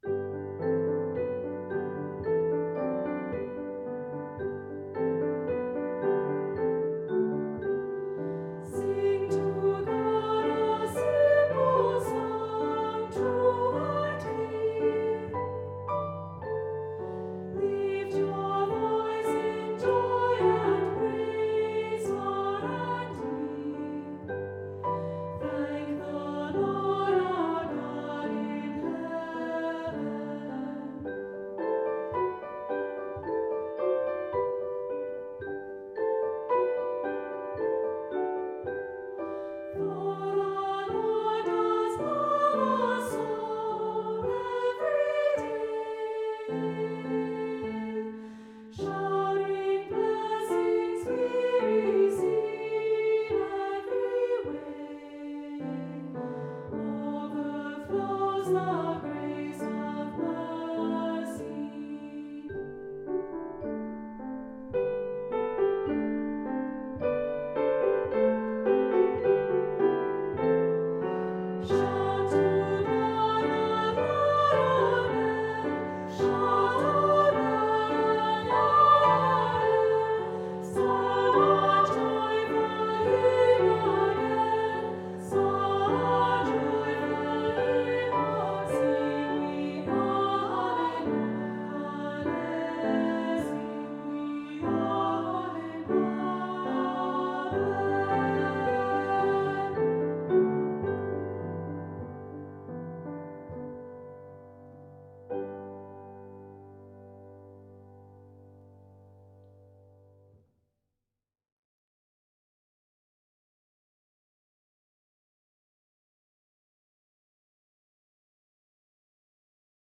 Voicing: Unison; Two-part equal